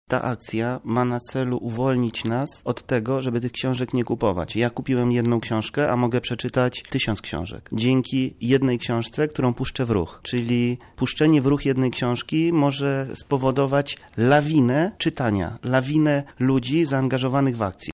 jeden z organizatorów.